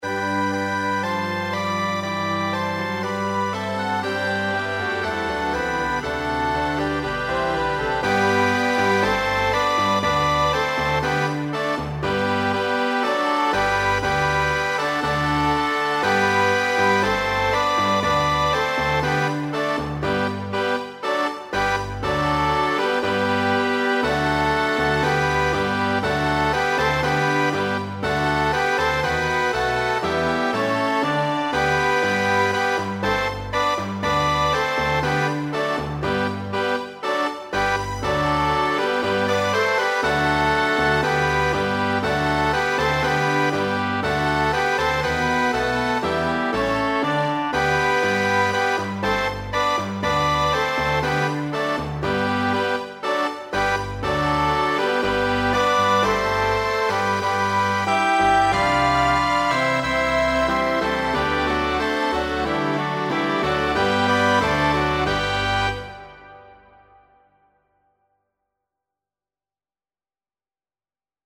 Orchestre Hymne à la joie en SOL.mp3